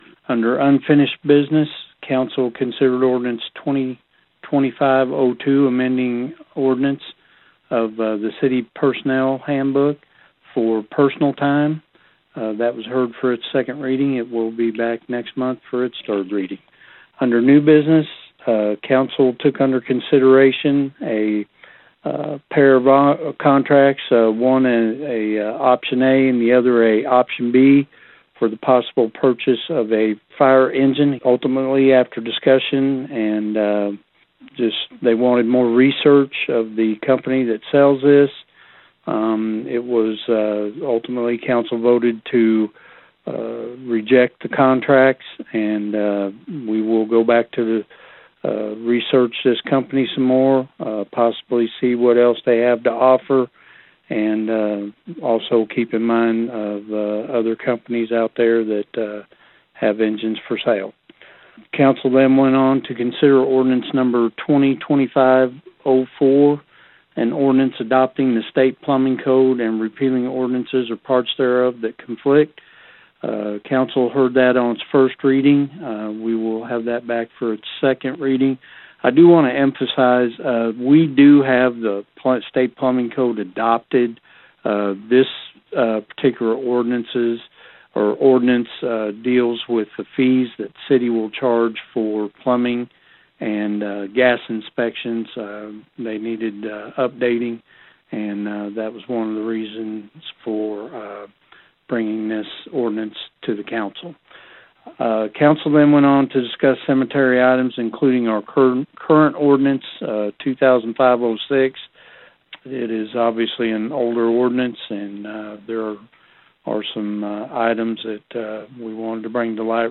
In a meeting that lasted nearly two hours the Gassville City Council was in session Tuesday evening covering a range of business including new potential contracts for the purchase of a new fire engine.
Mayor Jeff Braim shared the following update as well as further clarifiying that the state code for plumbing is adopted but the fees that the city will charge for inspections for plumbing and gas needed to be updated.